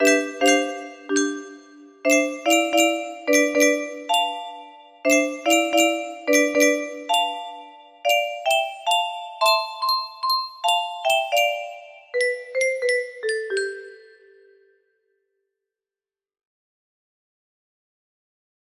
Clone of Unknown Artist - Untitled2 music box melody